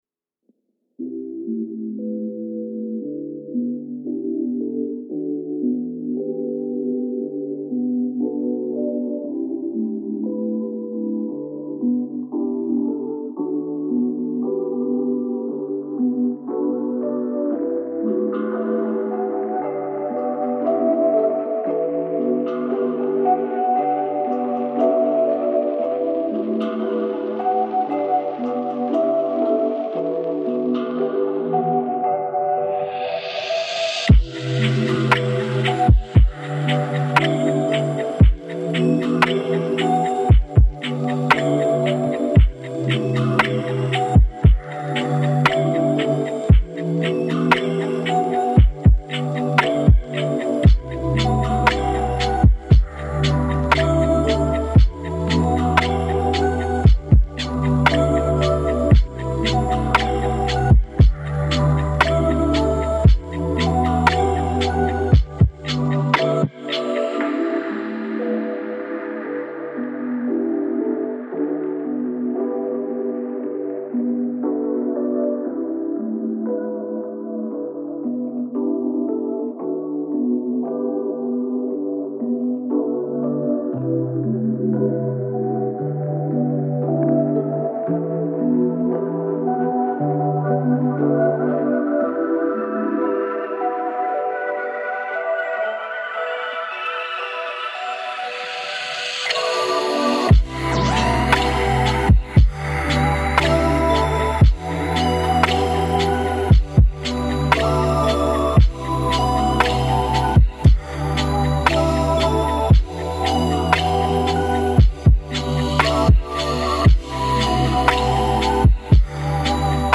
Красивая музыка без слов для творчества и работы на фоне